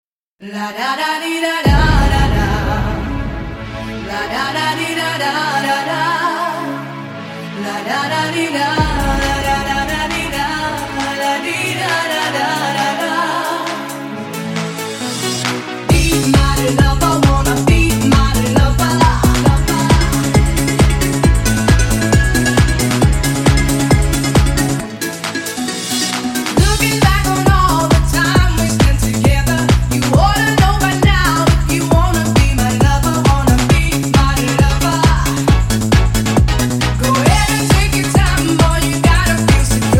евродэнс
танцевальные